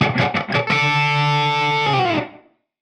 AM_HeroGuitar_85-D02.wav